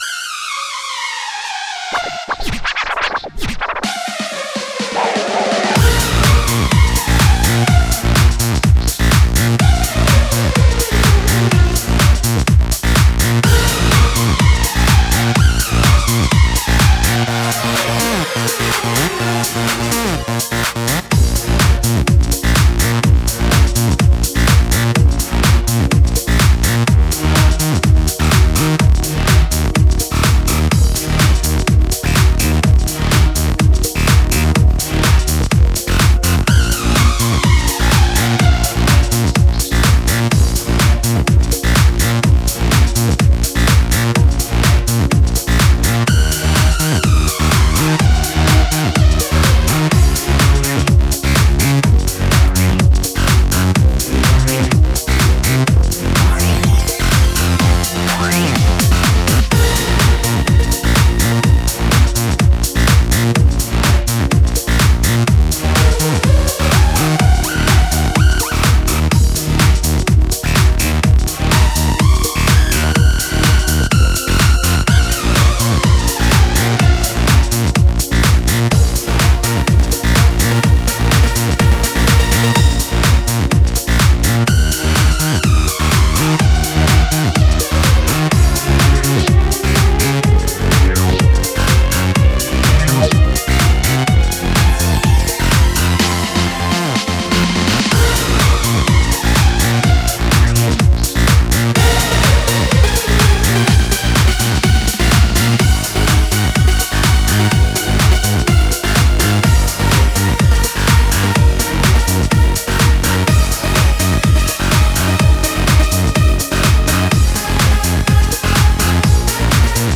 Российский поп